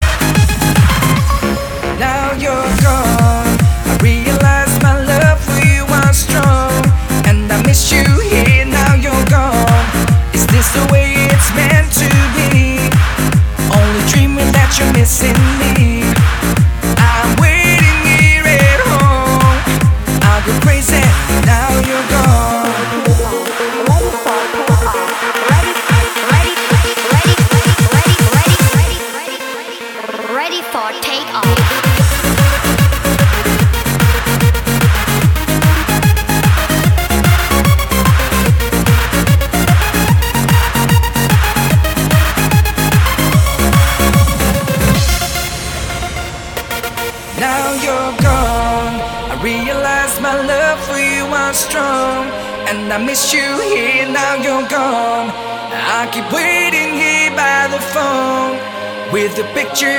• Качество: 256, Stereo
громкие
Trance
Eurodance
Hard House
europop